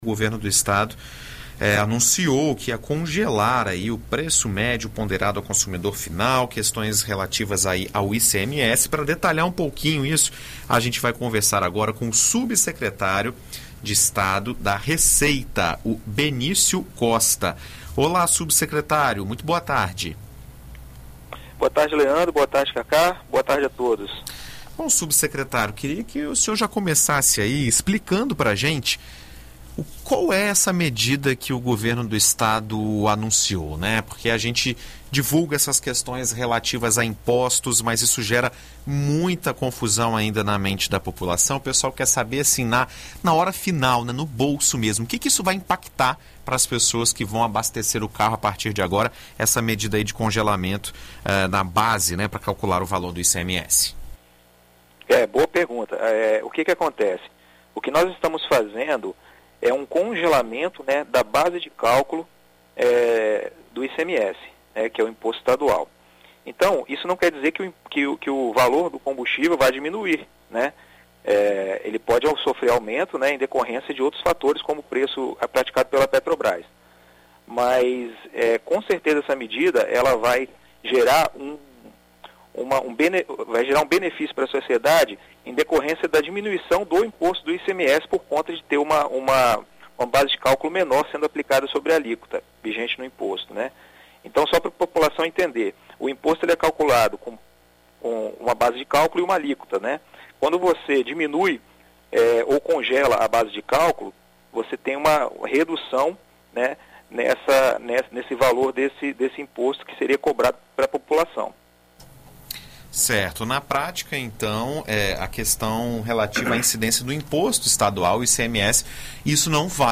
Na BandNews FM, o subsecretário de Estado da Fazenda, Benício Costa, explica como vai funcionar o preço dos combustíveis no estado
Em entrevista à BandNews FM Espírito Santo nesta terça-feira (28), o subsecretário de Estado da Fazenda, Benício Costa, explica como vai funcionar o preço dos combustíveis no estado.